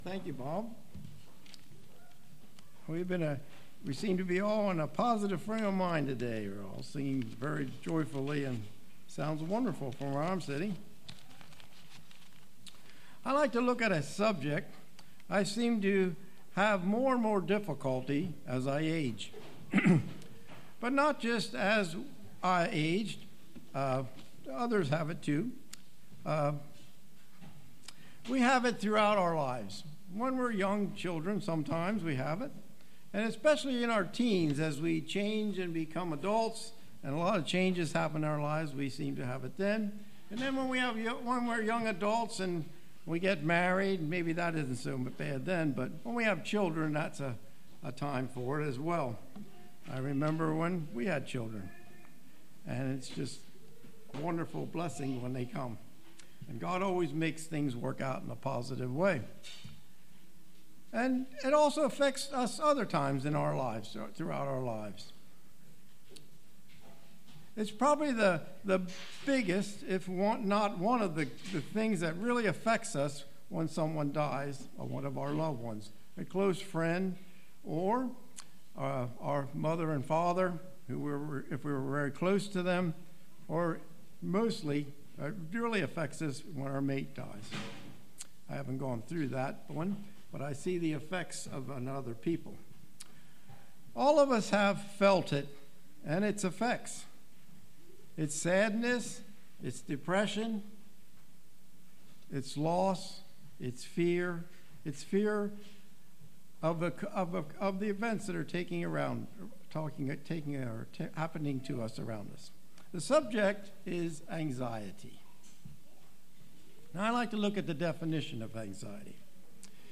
Sermons
Given in Lewistown, PA